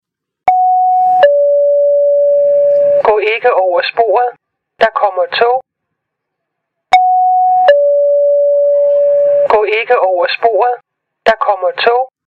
Kategori Alarm